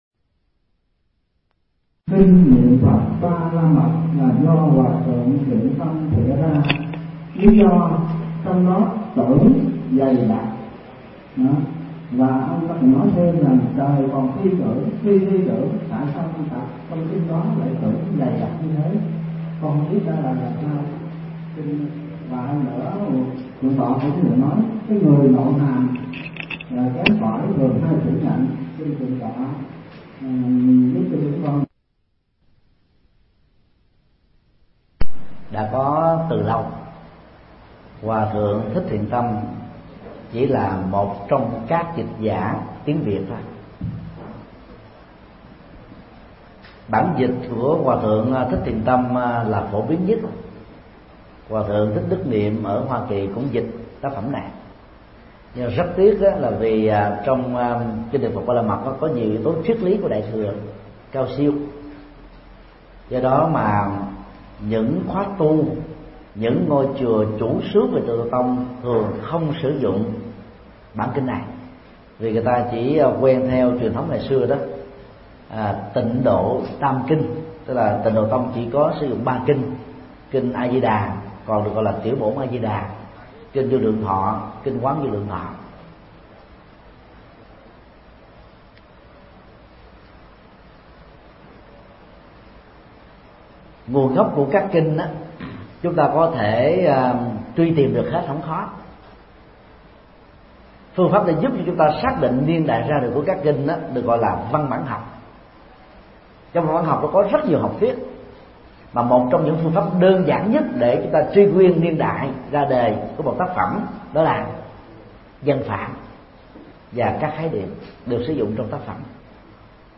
Vấn đáp: Kinh niệm Phật Ba La Mật – Thượng Tọa Thích Nhật Từ mp3